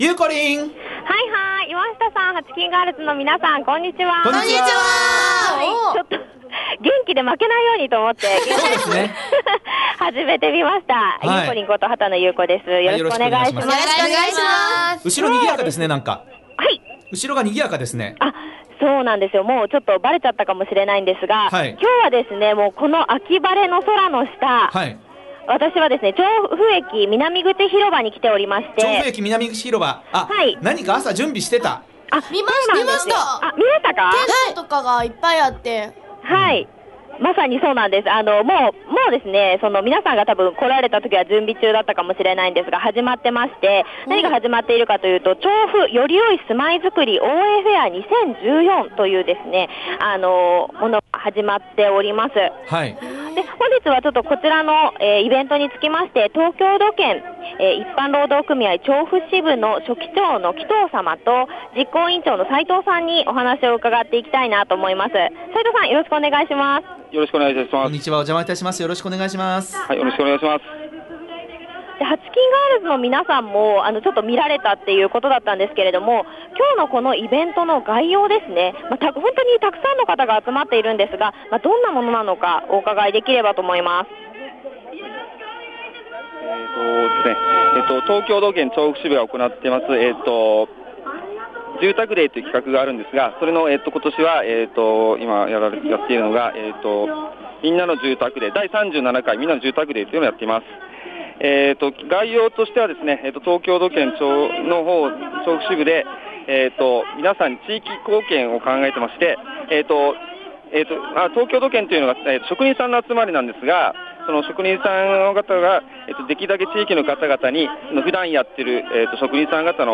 街角レポート
本日は調布駅南口 住宅デーにお邪魔してきました☆